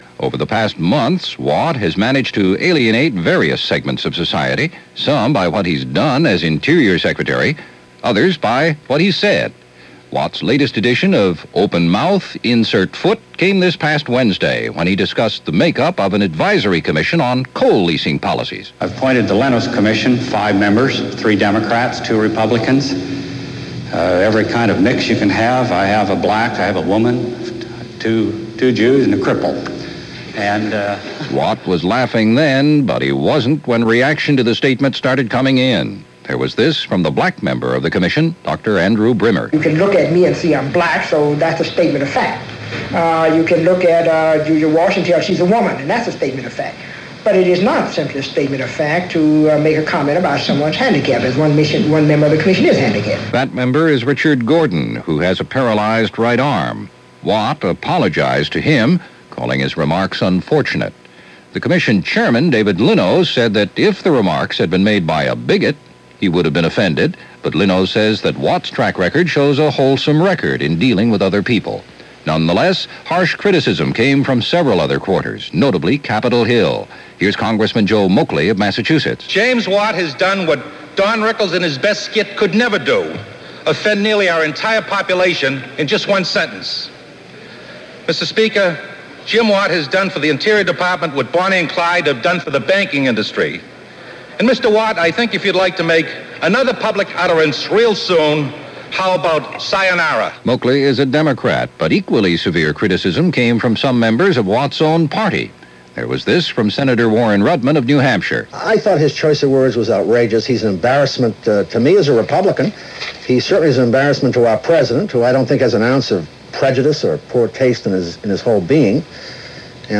During an address, explaining the diversity of the members of the “U.S. Commission on Fair Market Value Policy for Federal Coal Leasing” he offered this somewhat maladoit description:
So as a reminder of that Stupid is Timeless, here is that news report, via ABC’s World News This Week.